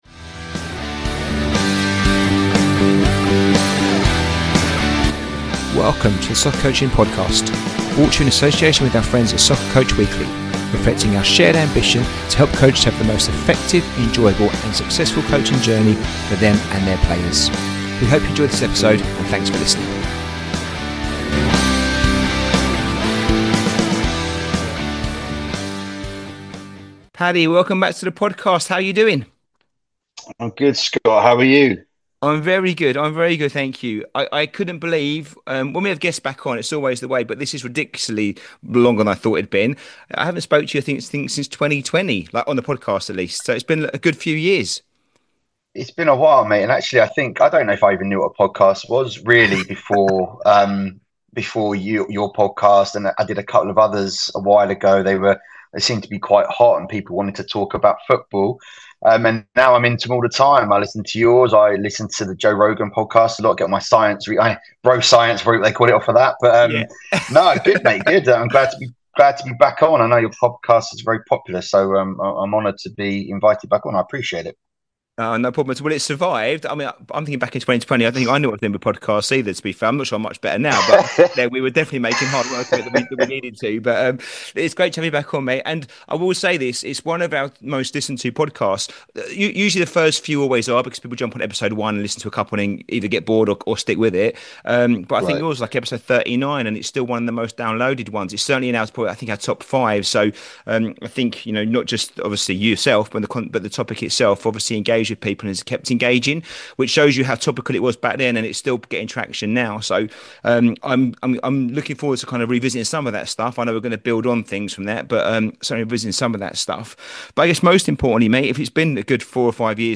Episode 116 - Positional Play, a conversation